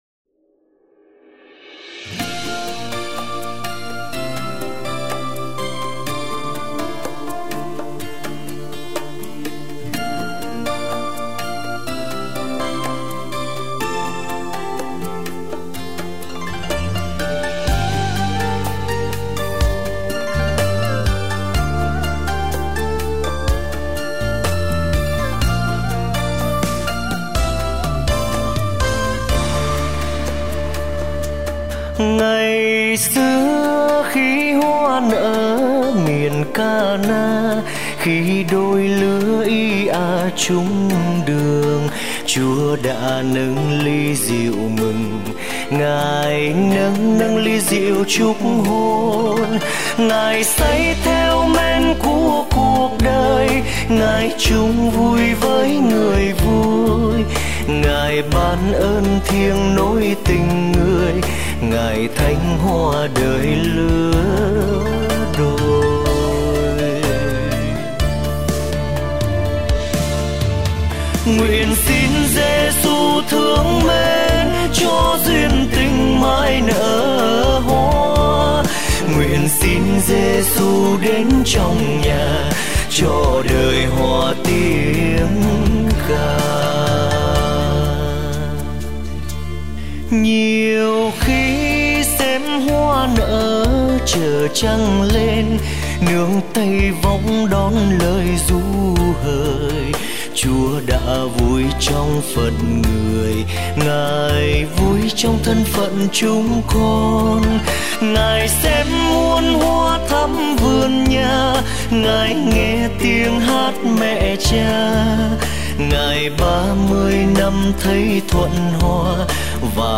Lễ Cưới